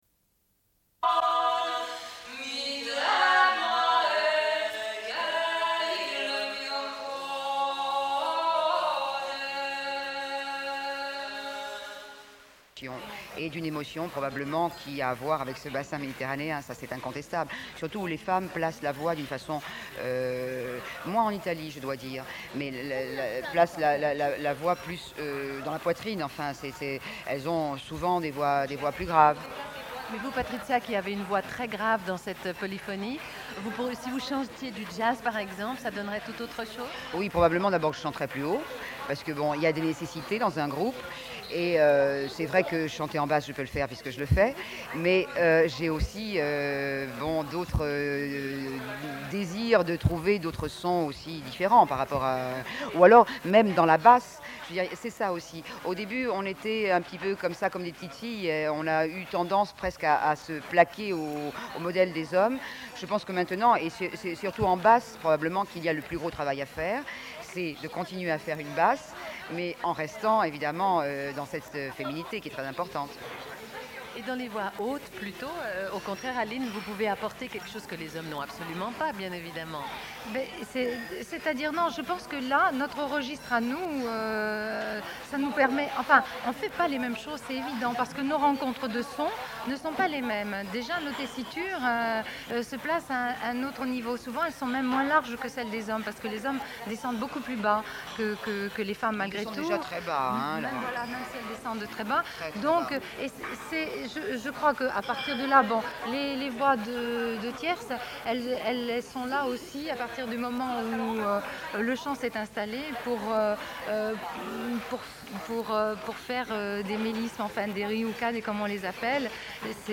Une cassette audio, face B29:15